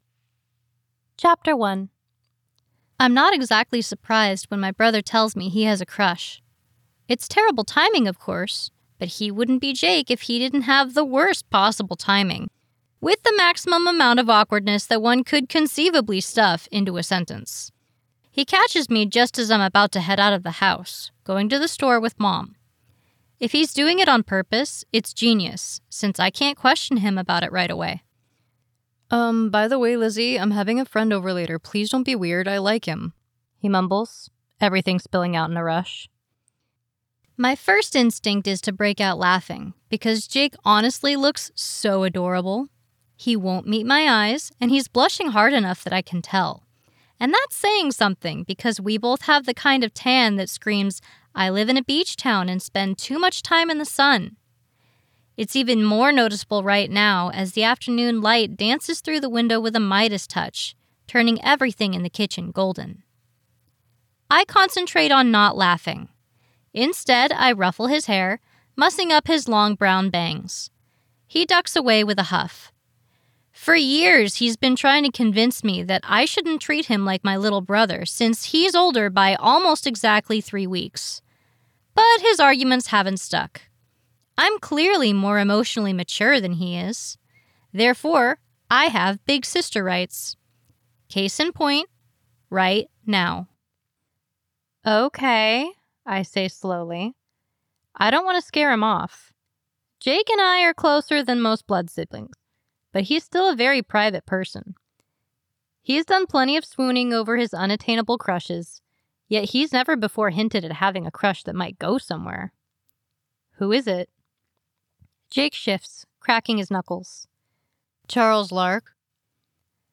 All That Pride By Livia Janes [Audiobook]